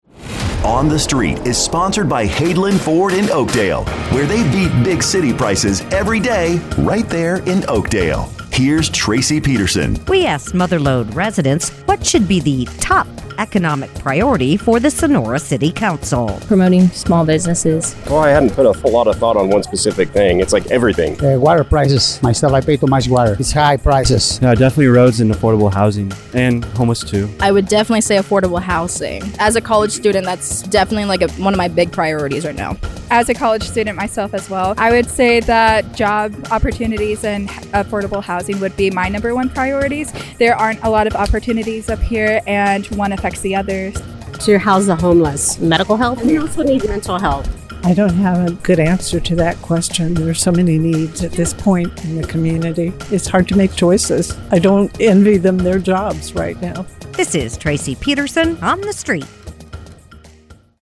asks Mother Lode residents, “What should be the top economic priority for the Sonora City Council?”